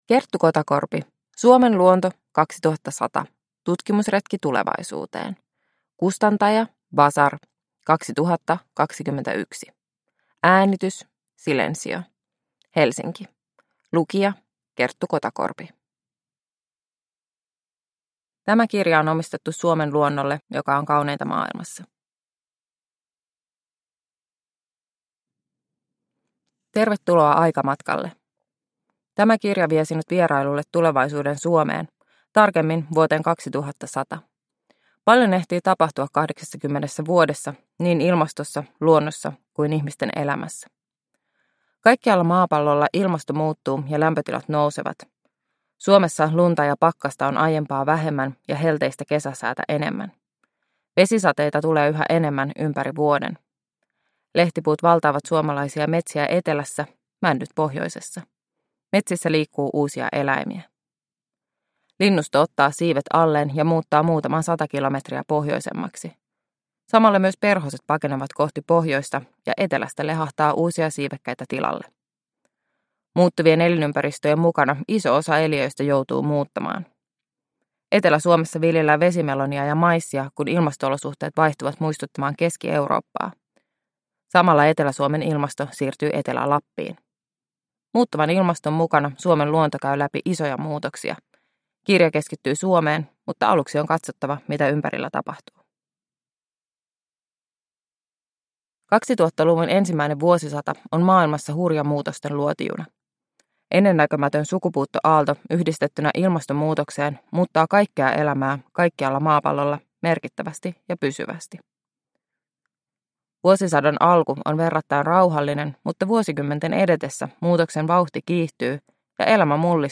Suomen luonto 2100 – Ljudbok – Laddas ner
Uppläsare: Kerttu Kotakorpi